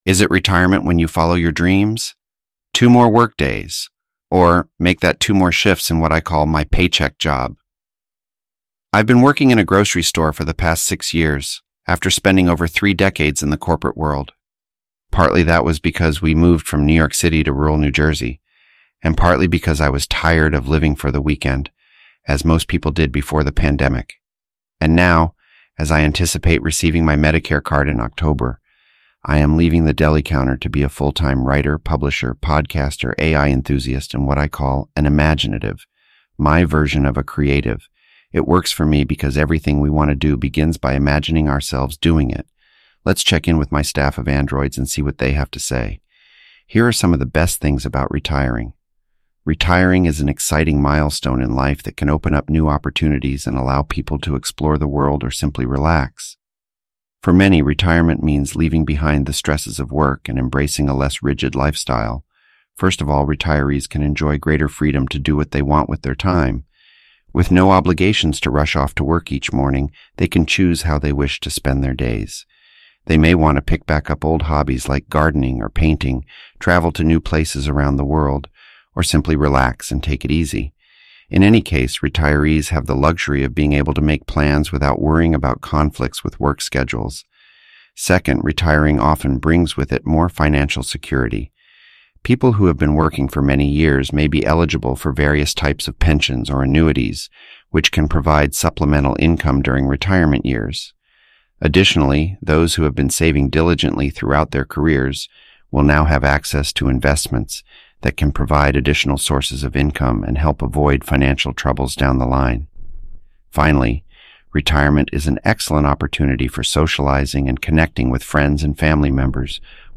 synthesized_audio-8.mp3